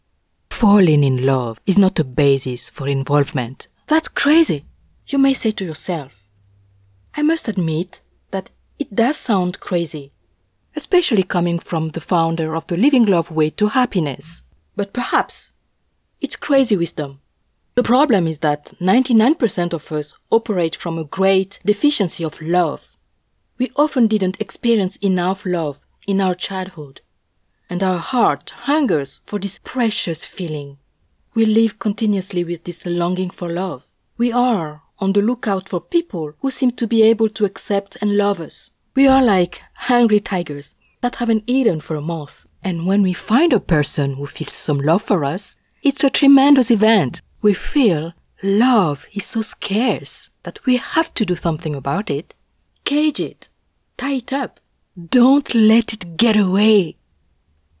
Audio Book Samples
Non-Fiction (English)
Thought-provoking, Vibrant